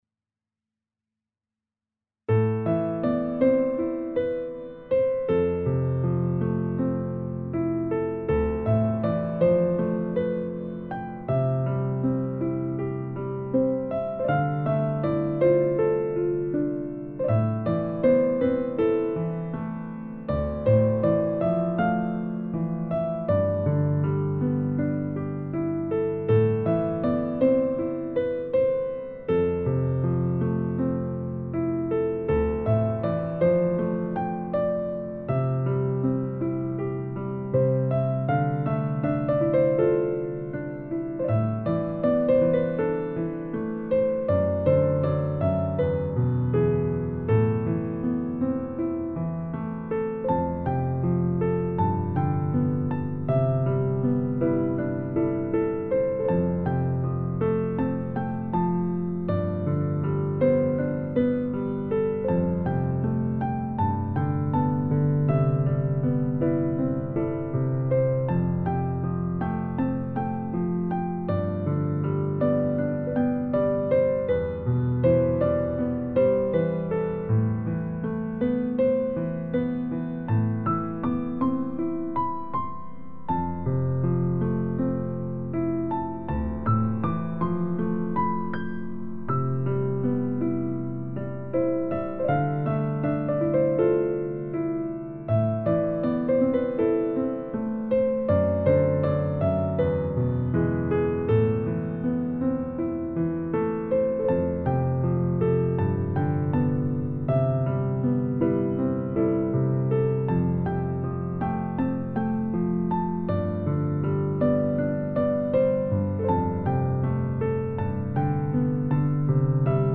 夜，我在街边独自走着，不觉地唱起了歌，却是那么忧伤的旋律。